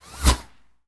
bullet_flyby_07.wav